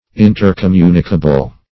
Search Result for " intercommunicable" : The Collaborative International Dictionary of English v.0.48: Intercommunicable \In`ter*com*mu"ni*ca*ble\, a. Capable of being mutually communicated.